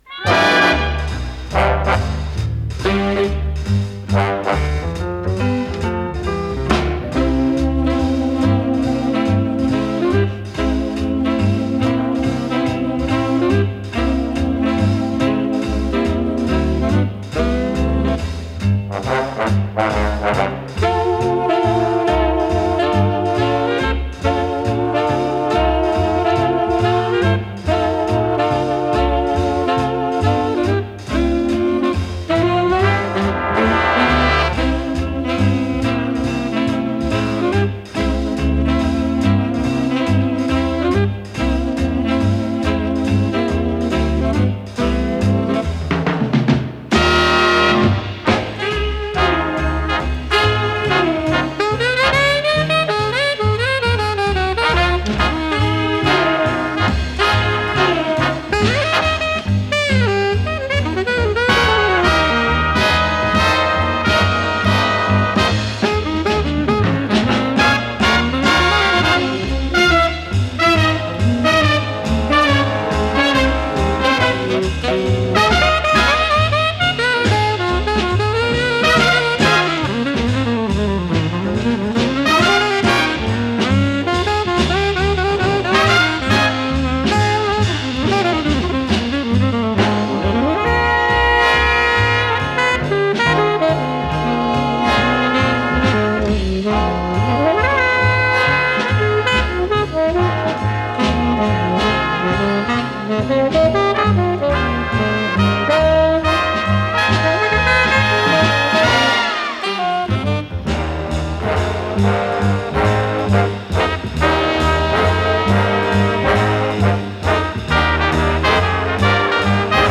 с профессиональной магнитной ленты
сакс-альт